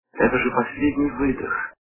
» Звуки » Из фильмов и телепередач » Кин-дза-дза - Это же последний выдох
При прослушивании Кин-дза-дза - Это же последний выдох качество понижено и присутствуют гудки.